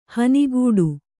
♪ hanigūḍu